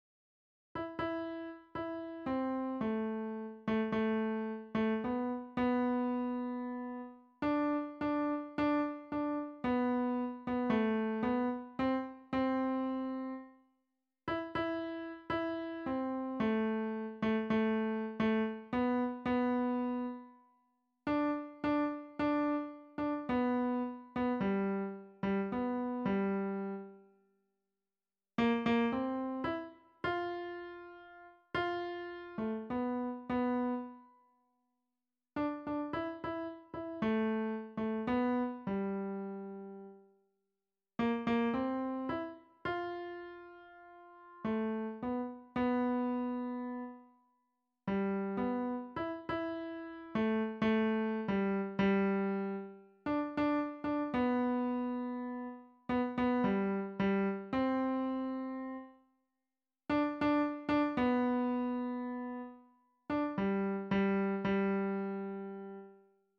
Sangtrim på eldresenteret
Odd-Roar Sangkor hadde mandag ettermiddag sangtrim med de eldre på Østbyen Eldresenter.
små_gleder_alt_piano.mp3